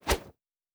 pgs/Assets/Audio/Fantasy Interface Sounds/Whoosh 04.wav
Whoosh 04.wav